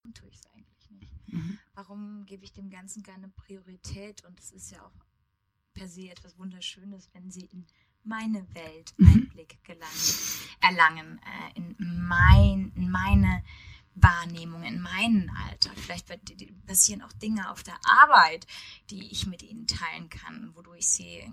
Wir haben 2 Podcastfolgen mit Netzbrummen und bekommen es einfach nicht raus.
Weil das Brummen mit der Gesamtlautstärke schwankt… Ansonsten hört sich das eher nach einem Anschnitt denn Sinus an - also eher die eklige Variante…